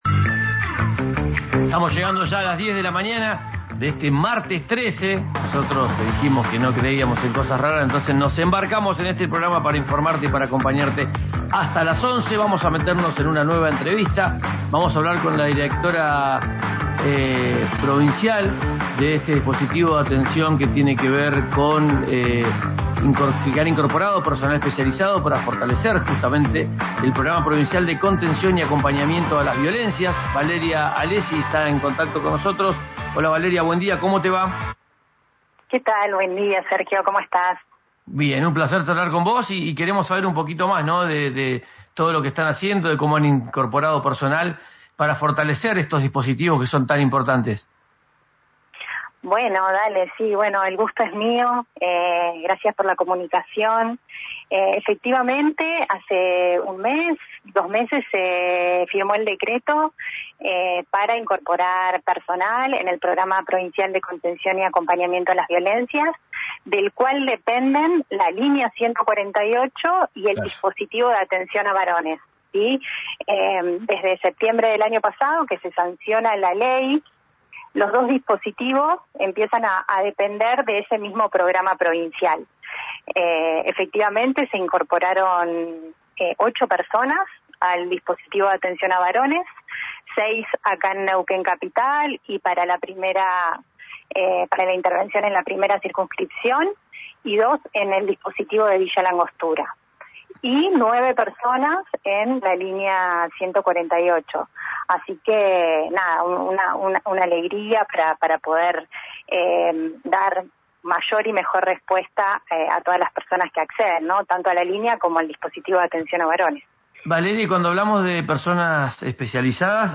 La directora provincial del área, Valeria Alessi, contó los detalles en RÍO NEGRO RADIO.